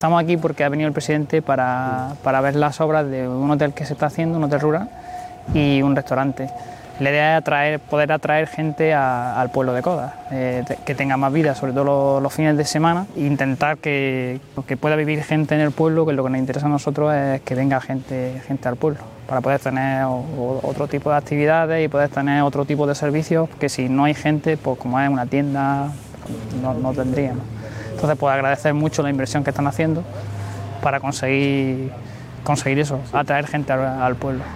15-01_raul_granero_alcalde_cobdar.mp3